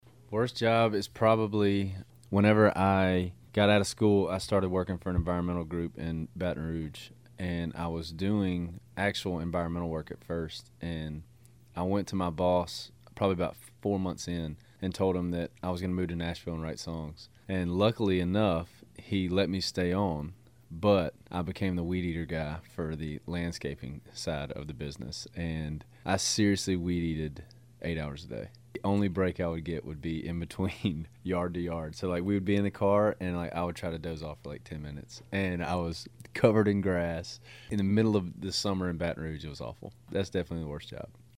Audio / JORDAN DAVIS, WHOSE DEBUT SINGLE IS MAKING ITS WAY UP THE COUNTRY CHARTS, TALKS ABOUT HIS WORST JOB.